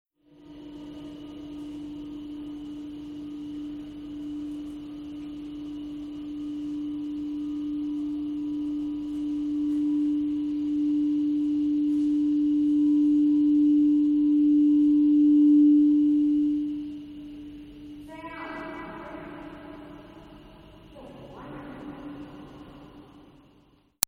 This installation was created in the Queen's Powder Magazine on Goat Island, a nature reserve in Sydney Bay.  The building is an enormous former munitions warehouse with very thick sandstone walls and an arched roof, built around 100 years ago.
Two sets of scaffolding at each end of the space held speakers bouncing the sound off the arch, source was a single PZM microphone situated around head height half way along one side wall.  A nearby boatyard on the island contributed metallic crashes and grinding noises which inspired the system to respond, not always predictably.
Scale - a combination of scale and volume of the building and the available permutations of positioning the system was slightly too large to enable the presence of human bodies in the space to modulate the feedback notes - this did not prevent visitors becoming part of the experience by making noise - footsteps, speaking or singing.
A documentary fragment of a moment within the installation, recorded on 23 September 1998